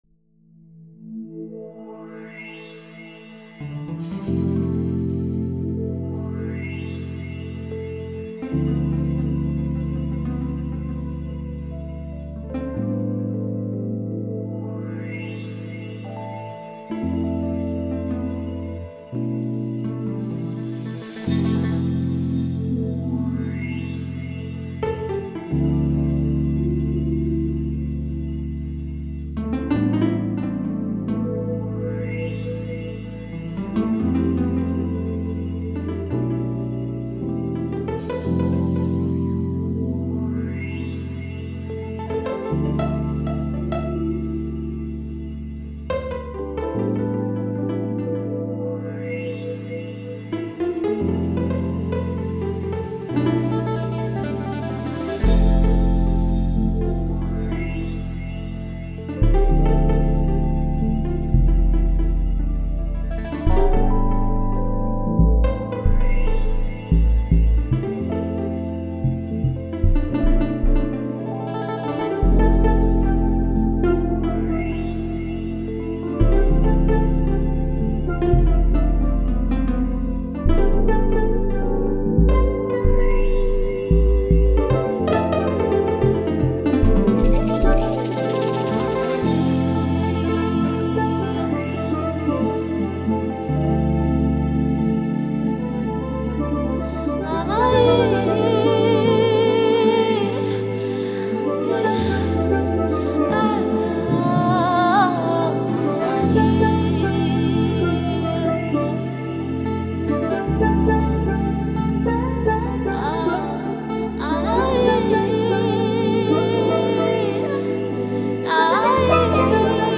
Home » Amapiano » Gospel » Hip Hop